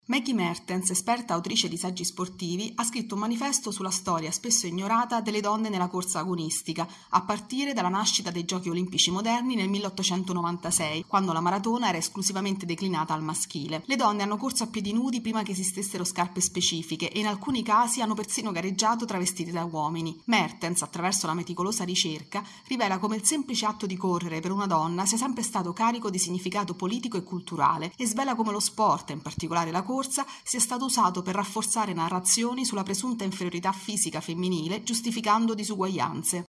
Sport
Il libro di Maggie Mertens svela come le donne siano state sistematicamente escluse dalla scena sportiva. Il servizio